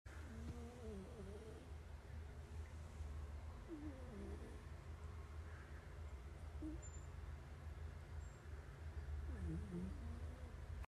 Goofy Snores Like As If Sound Effects Free Download